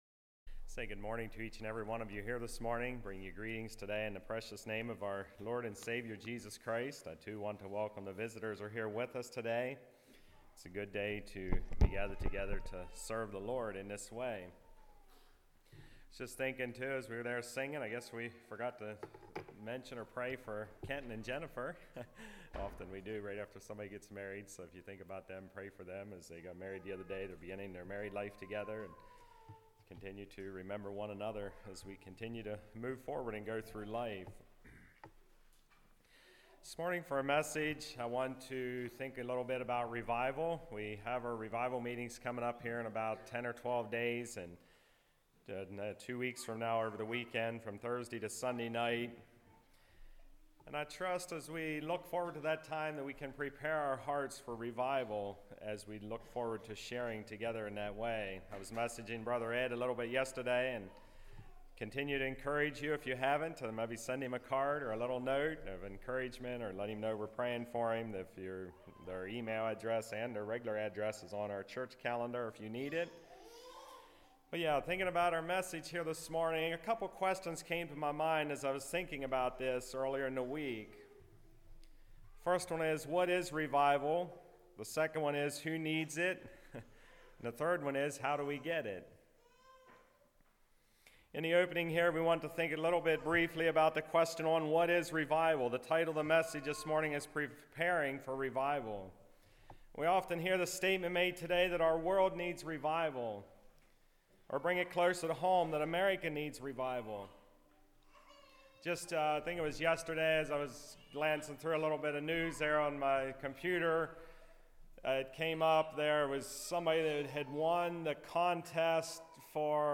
Service Type: Message